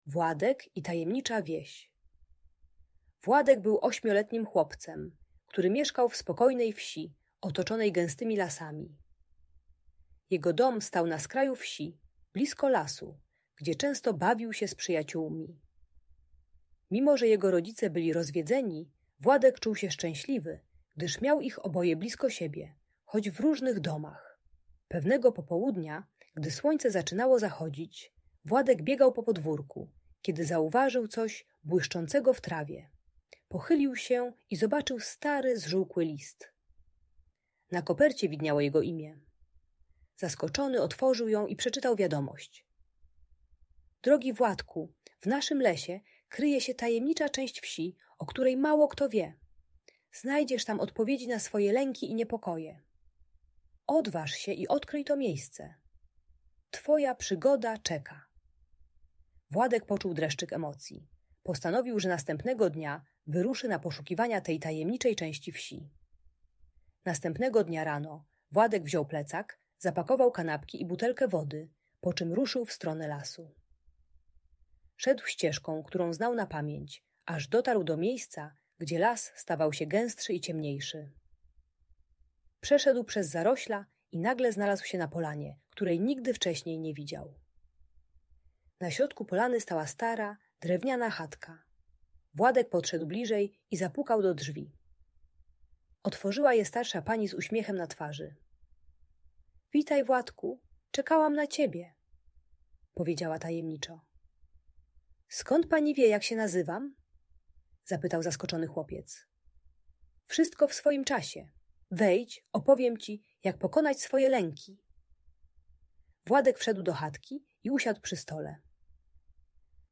Tajemnicza wieś - Lęk wycofanie | Audiobajka